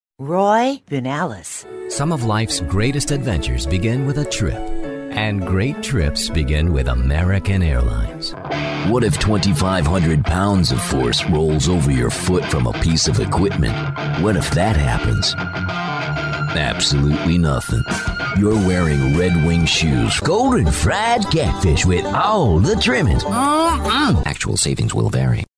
Commercial
Middle Aged
Broadcast Quality In-Home Recording Studio
Sennheiser MKH-416 Shotgun Microphone
Filipino-American Male, Neutral-accented North American English, working voice actor. A clear, full, deep, melodious, well-projected male bass-baritone voice. Interpretation is conversational, one-on-one, involved, sincere, warm, friendly, and enthusiastic. Pronunciation is crisp, clear, and accurate. Reading is smooth, precise, and confident, with a conversational flow.